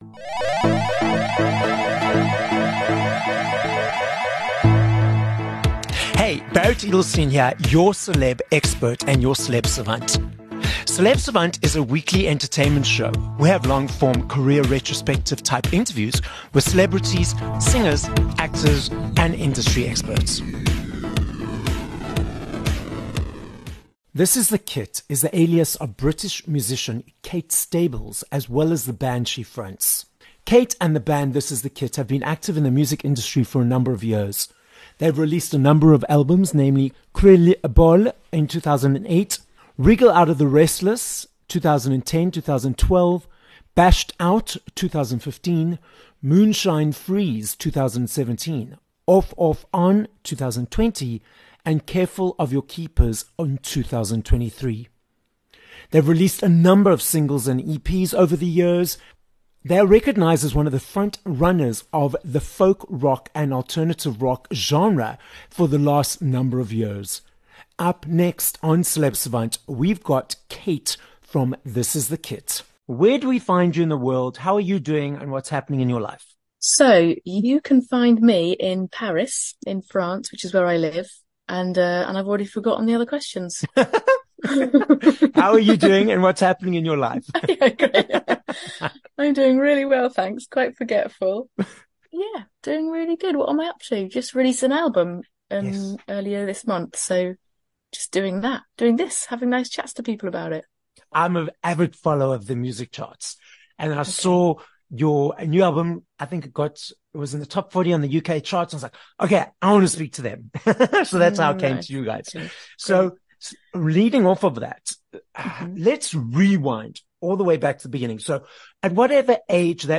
27 Jul Interview with This Is The Kit